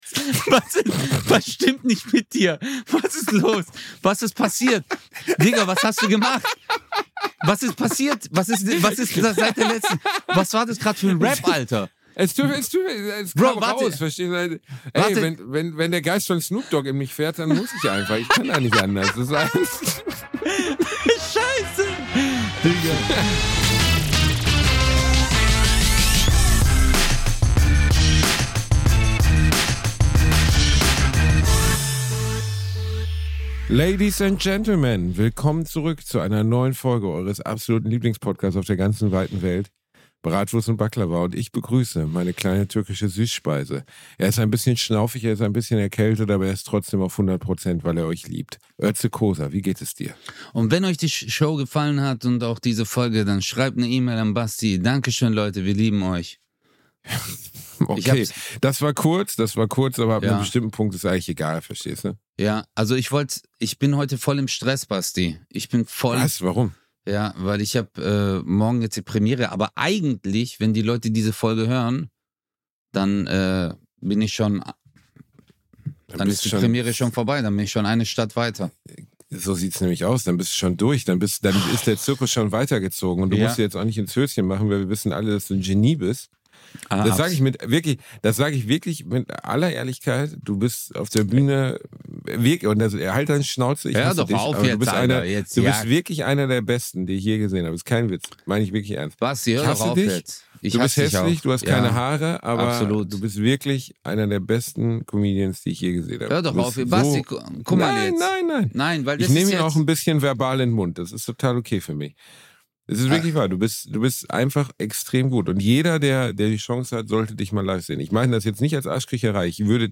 Basti rappt diese Folge.